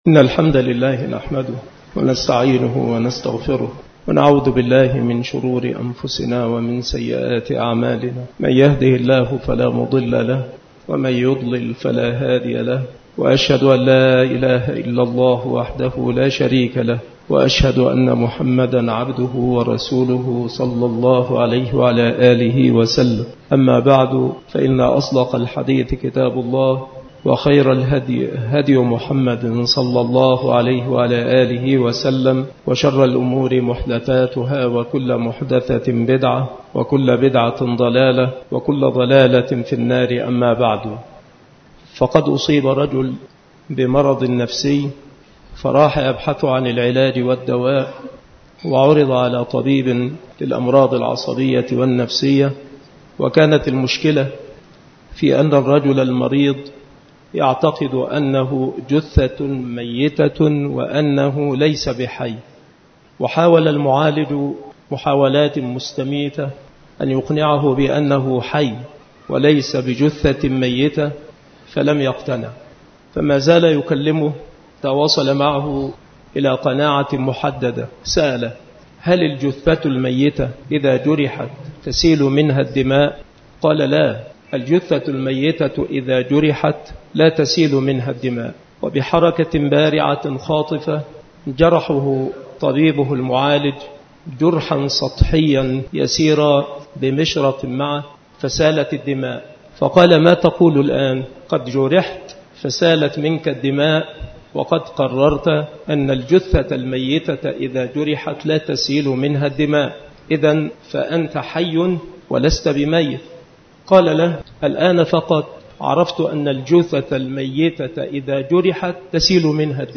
المحاضرة
مكان إلقاء هذه المحاضرة بالمسجد الشرقي - سبك الأحد - أشمون - محافظة المنوفية - مصر